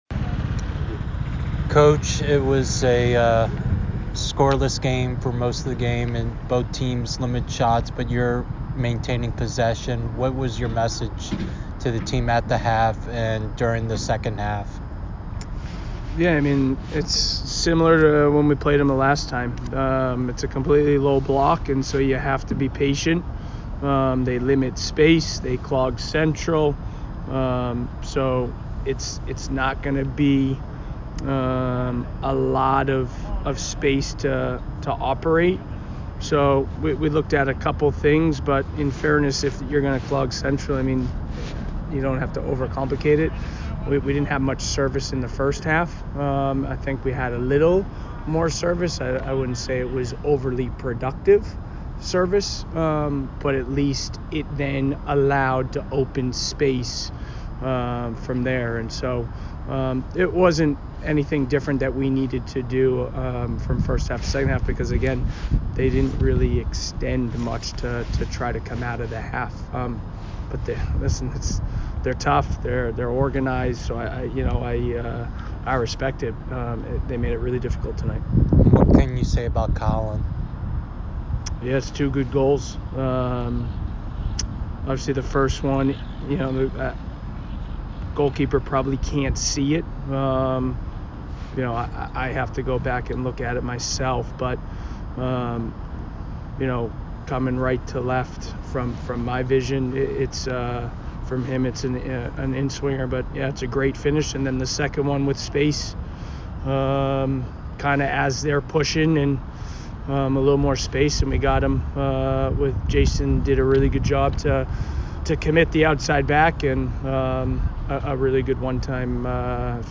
Colgate Postgame Interview